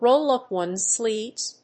アクセントróll úp one's sléeves